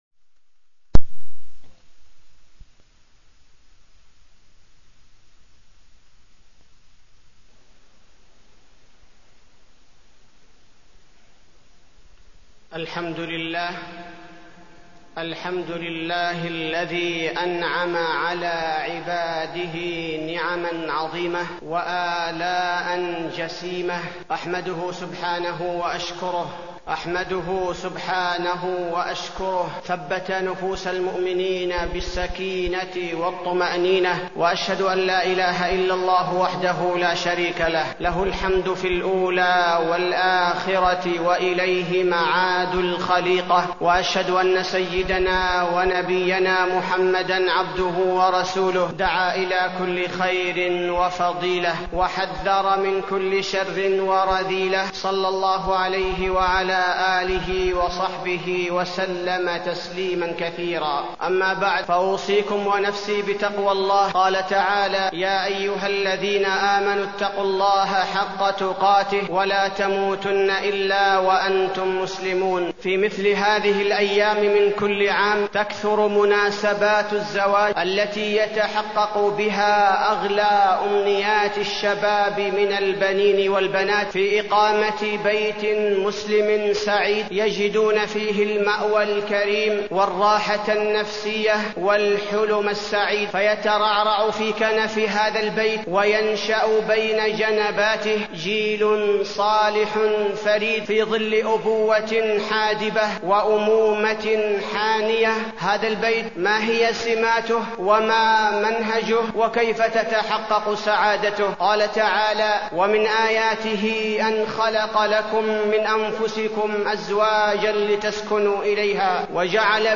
تاريخ النشر ٢ جمادى الأولى ١٤٢٣ هـ المكان: المسجد النبوي الشيخ: فضيلة الشيخ عبدالباري الثبيتي فضيلة الشيخ عبدالباري الثبيتي الزواج ومنزلة الأسرة